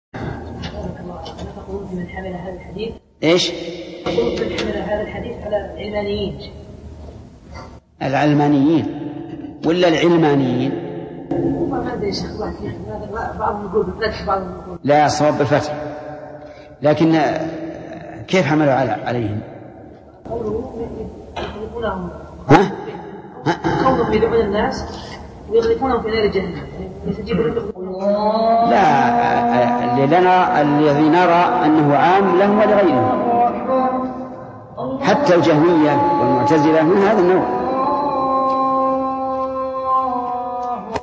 Download audio file Downloaded: 445 Played: 358 Artist: الشيخ ابن عثيمين Title: العلمانيون والجهمية والمعتزلة هم من الدعاة على أبواب جهنم Album: موقع النهج الواضح Length: 0:41 minutes (238.49 KB) Format: MP3 Mono 22kHz 32Kbps (VBR)